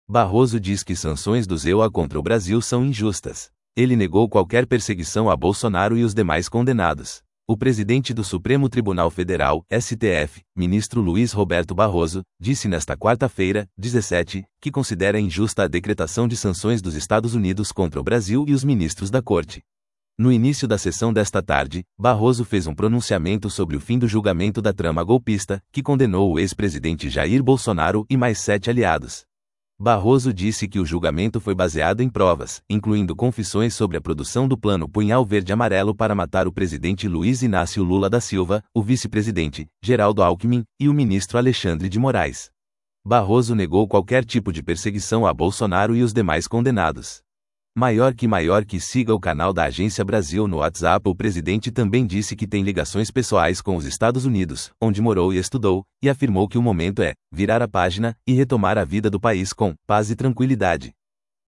No início da sessão desta tarde, Barroso fez um pronunciamento sobre o fim do julgamento da trama golpista, que condenou o ex-presidente Jair Bolsonaro e mais sete aliados.